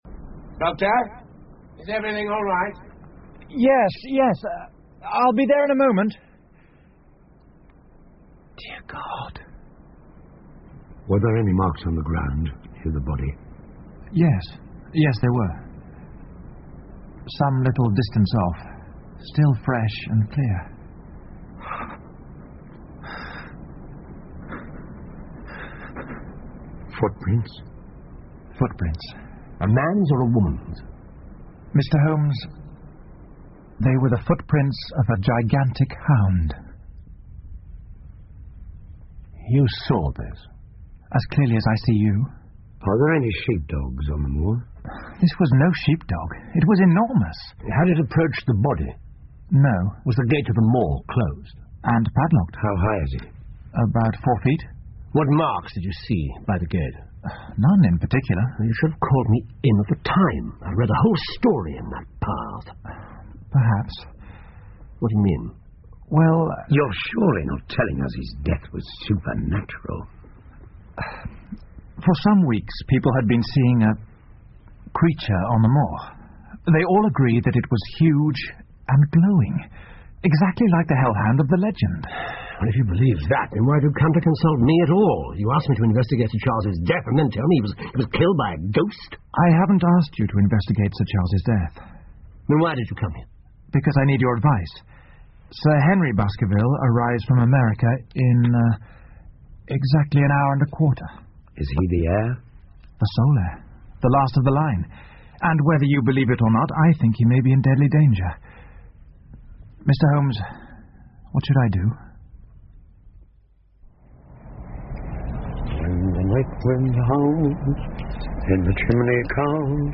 福尔摩斯广播剧 The Hound Of The Baskervilles - Part 01-4 听力文件下载—在线英语听力室